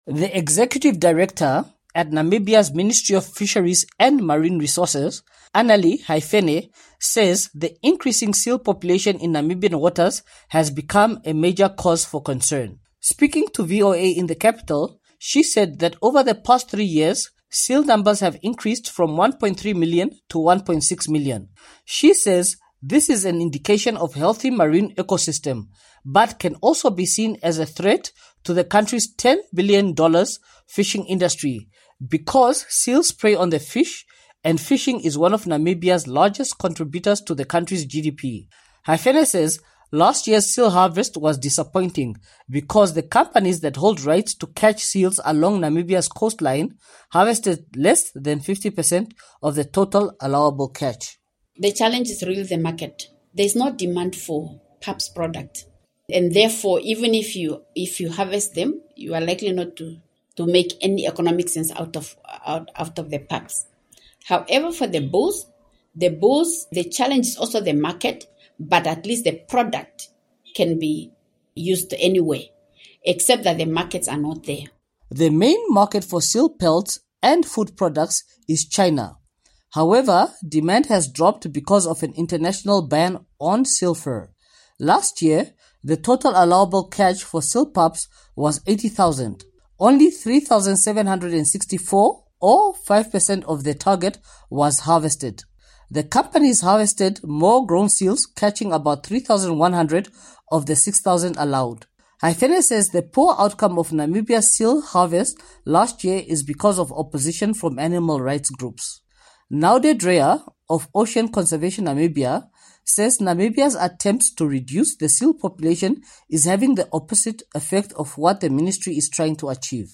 reports from Windhoek, Namibia